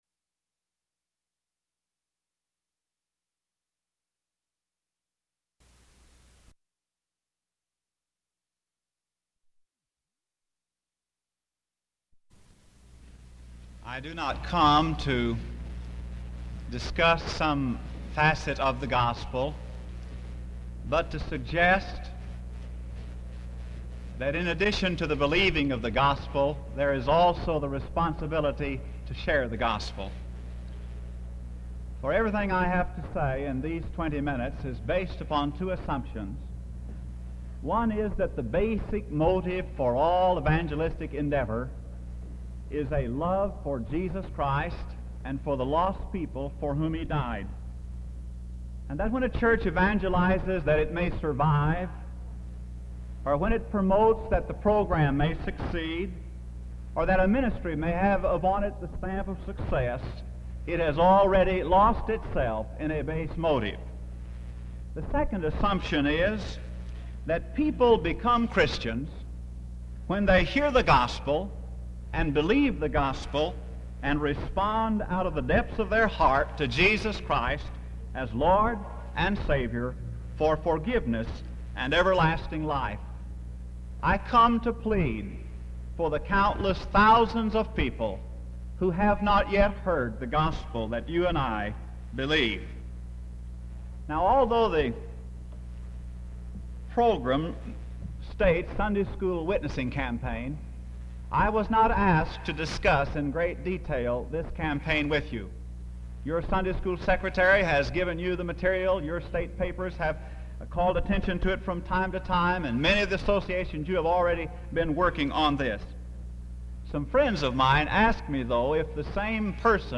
The 1963 Pastor’s Conference was held May 6-7, 1963, in Kansas City, Missouri.